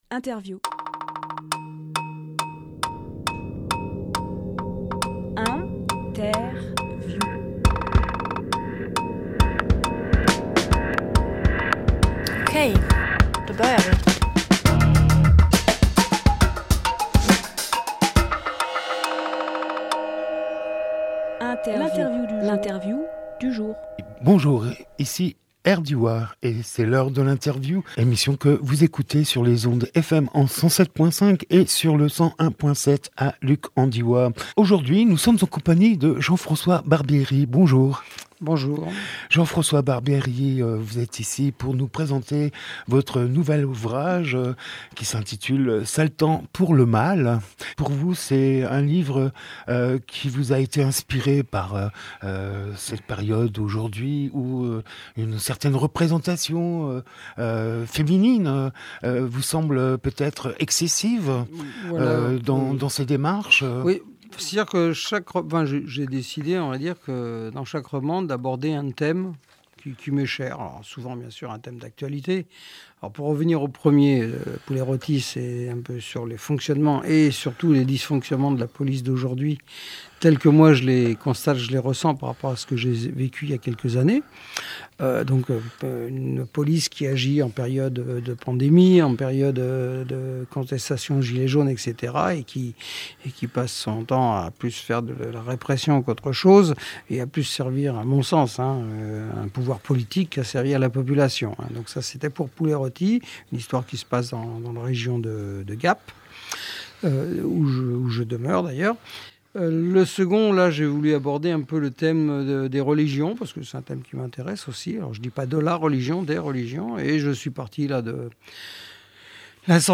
Emission - Interview Sale temps pour le mâle Publié le 29 août 2023 Partager sur…
21.08.23 Lieu : Studio RDWA Durée